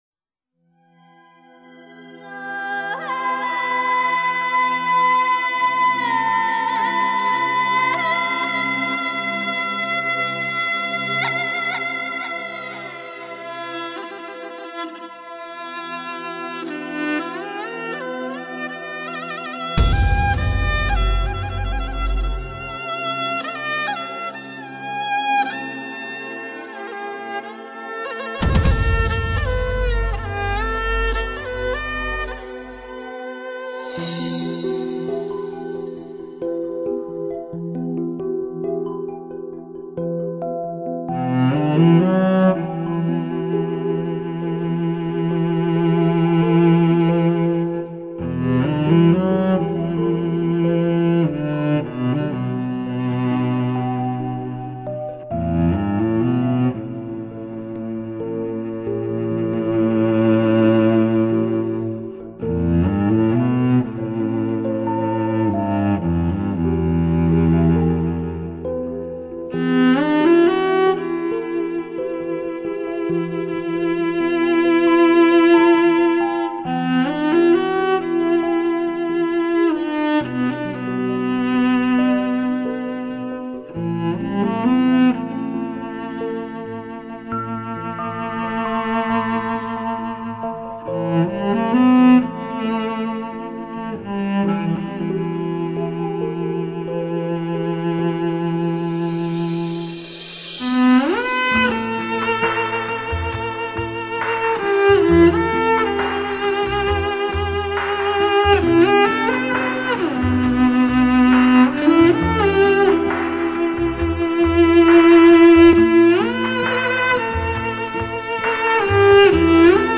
大提琴